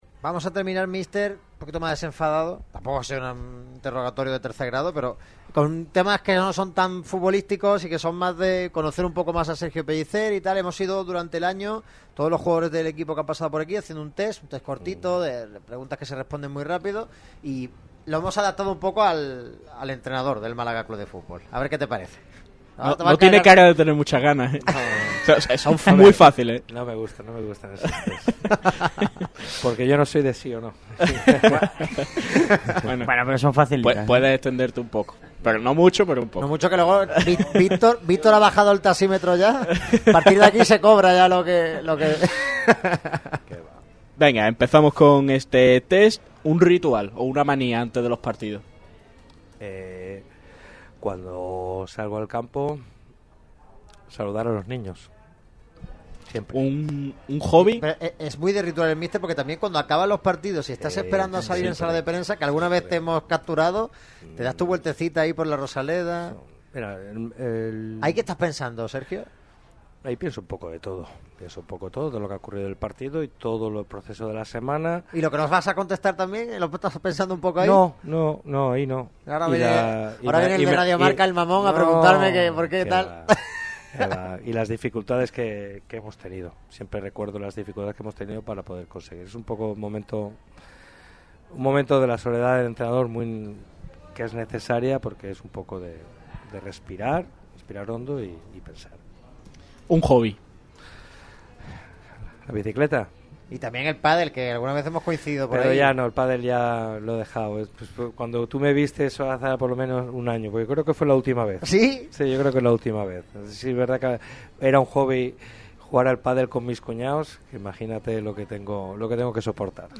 Entrevista y de las especiales en Radio MARCA Málaga. El entrenador del Málaga CF, Sergio Pellicer, ha atendido a la radio del deporte en una entrevista en exclusiva. A falta de la última jornada por disputar, el técnico de Nules ha hecho un repaso general a muchas cuestiones de interés. Valoración de la temporada, una reunión pendiente, nombres propios, aspectos personales, situaciones tácticas y mucho más.